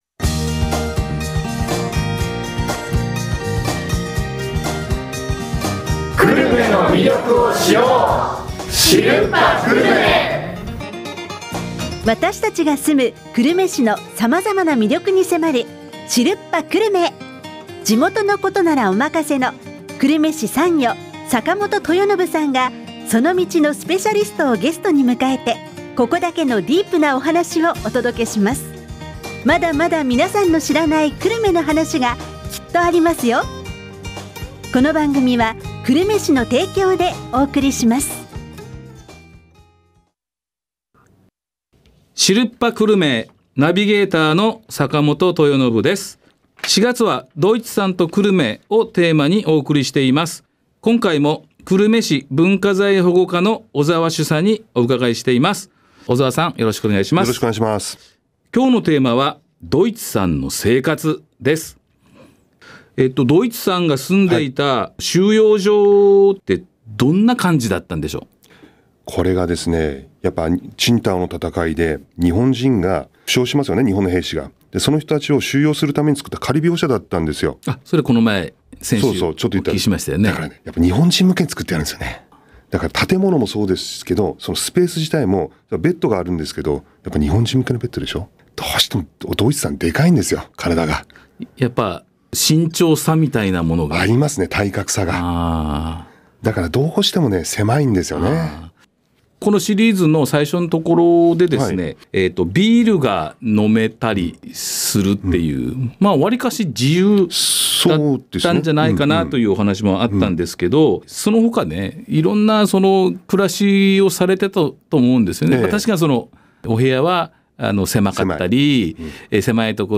ラジオ放送から1～2週間後に音声データとテキストを公開します。